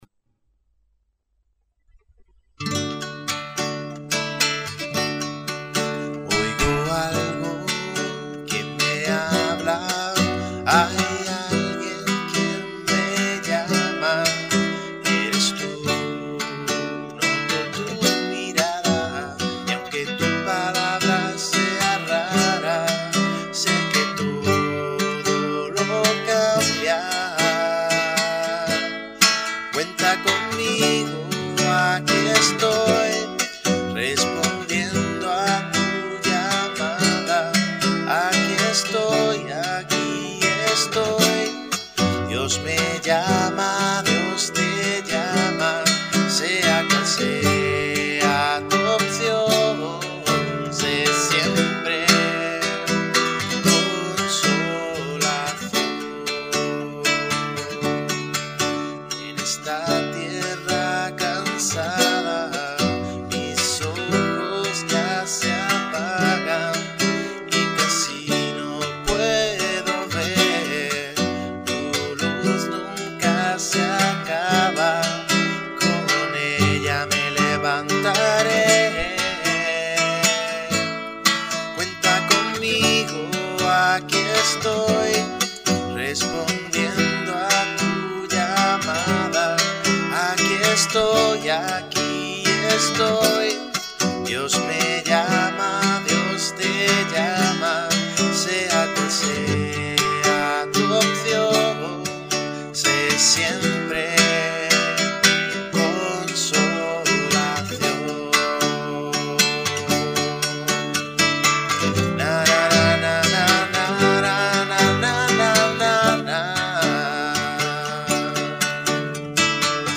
Cuenta Conmigo Demo: La primera Grabación